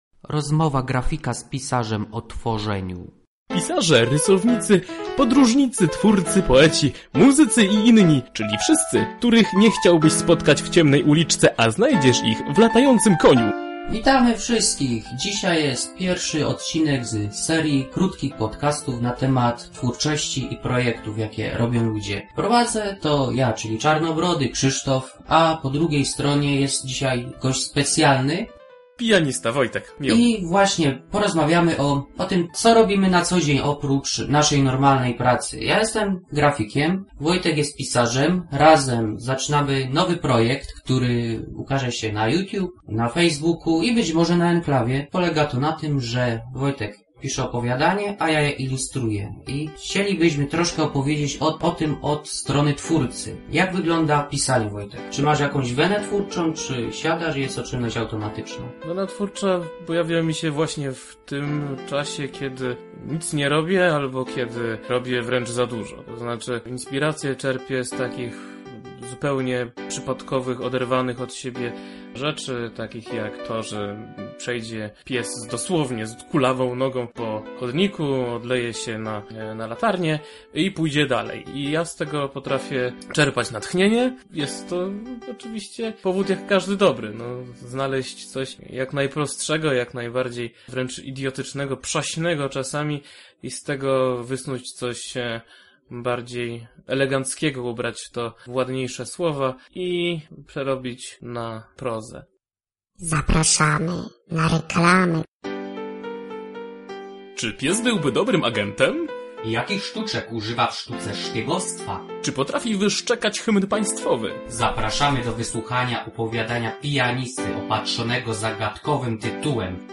Grafik z pisarzem rozmawiają o twórczości i tworzeniu.